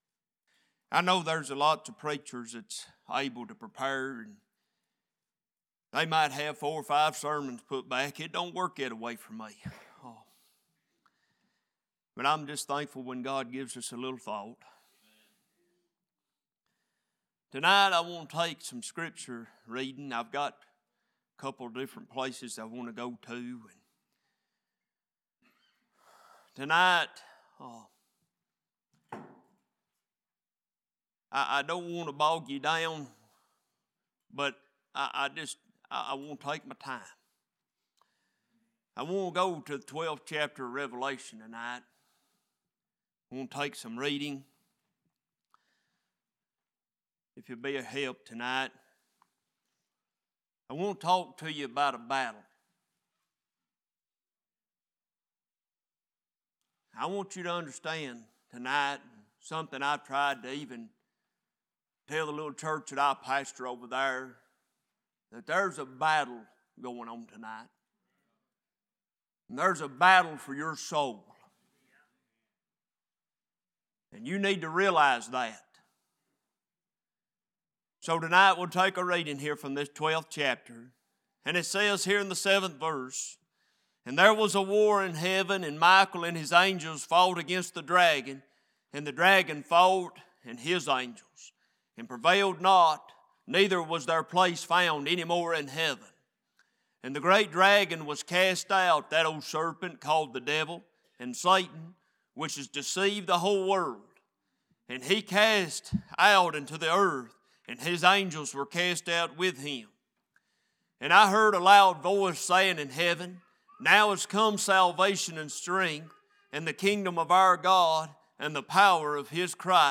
Revival Meeting Passage: Revelation 12:7-12, 1 Samuel 17:2-3, Exodus 14:13-14 Service Type: Worship « No Hiding Place What Is Holding You Back?